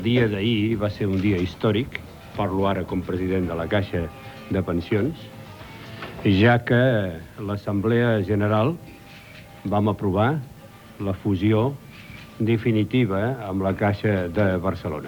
Joan Antoni Samaranch, president de Caixa de Pensions, anuncia que ahir el consell d'administració va aprovar la fusió de la Caixa de Pensions per a la Vellesa i d'Estalvis de Catalunya i Balears amb la Caixa de Barcelona,
Informatiu